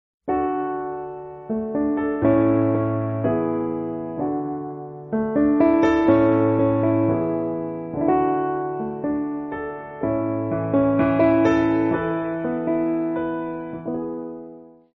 2 – نغمة عزف بيانو (piano_music)